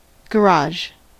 • ÄäntäminenUK:
• IPA: [ˈɡæɹ.ɒdʒ]